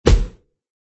throw.mp3